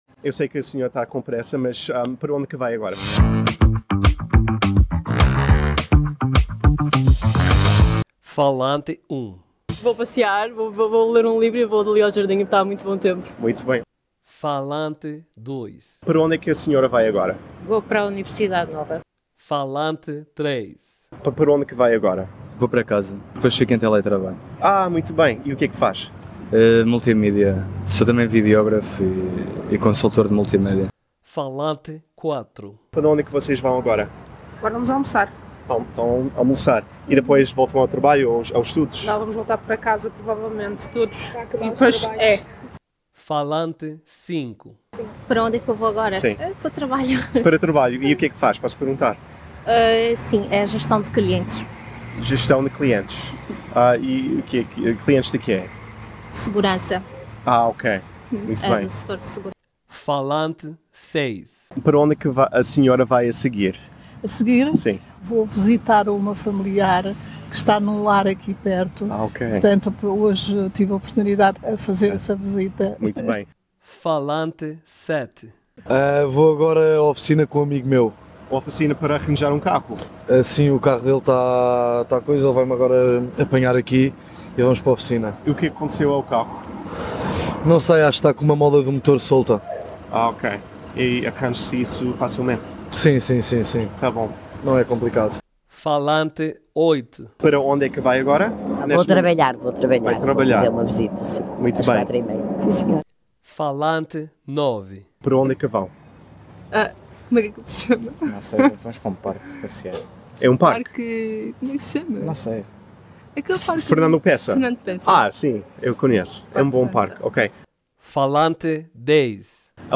Tirado e adaptado com fins educativos de Where are People in Lisbon going | Portuguese from Portugal do canal de Youtube Practice Portuguese